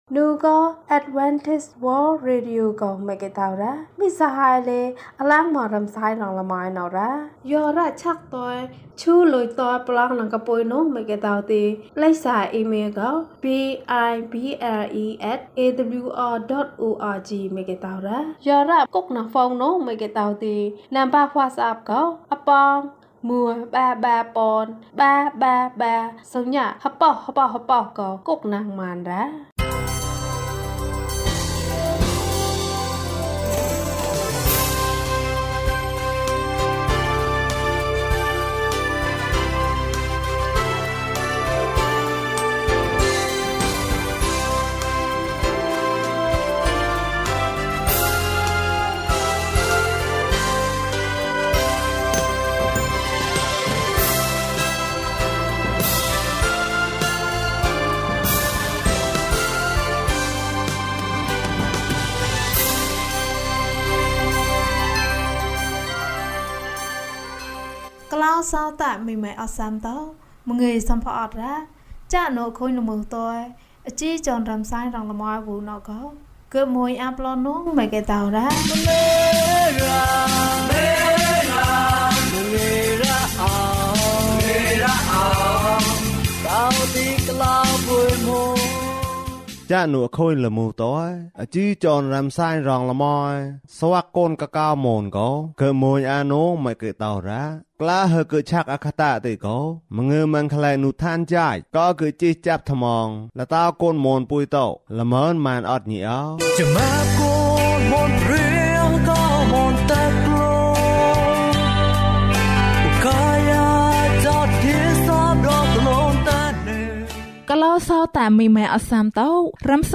ခရစ်တော်ထံသို့ ခြေလှမ်း။၃၃ ကျန်းမာခြင်းအကြောင်းအရာ။ ဓမ္မသီချင်း။ တရားဒေသနာ။